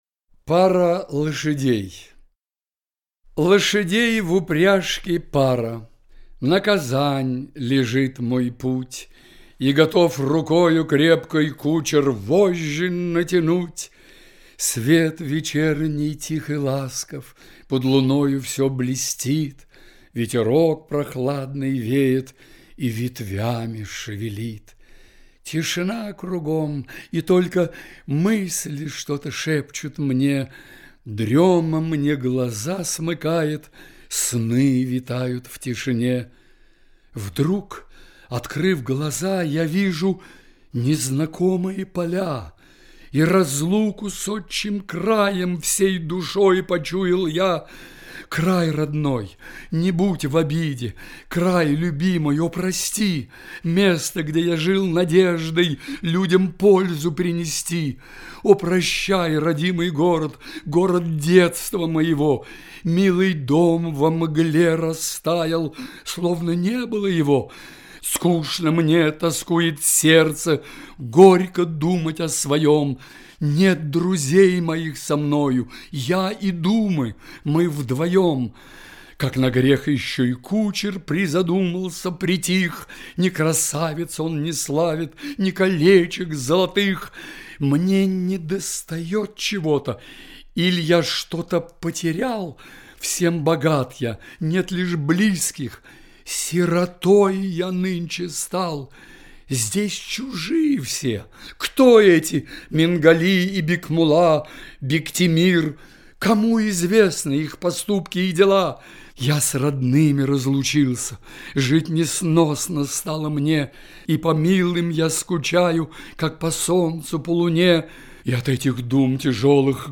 2. «Тукай (читает В.Лановой) – Пара лошадей» /